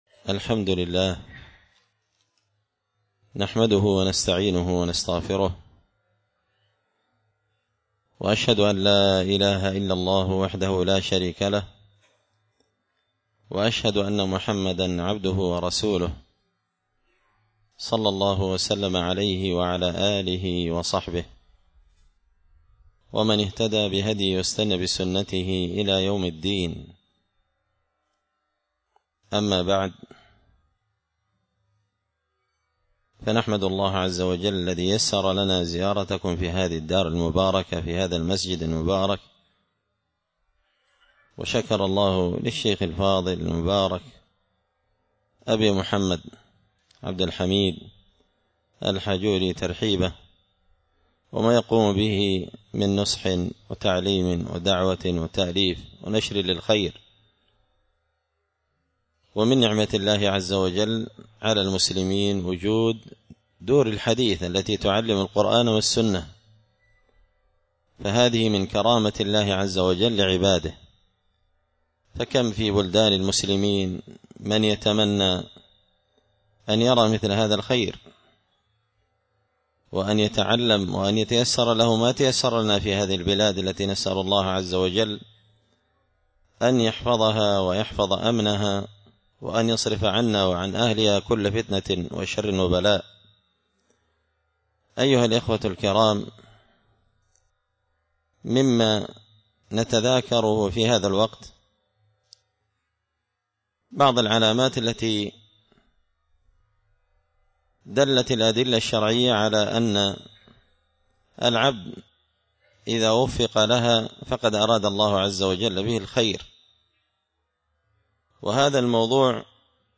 الجمعة 20 شعبان 1445 هــــ | الخطب والمحاضرات والكلمات | شارك بتعليقك | 82 المشاهدات